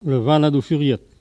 Patois - archives
Catégorie Locution